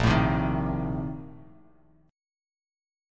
A#M7sus2sus4 chord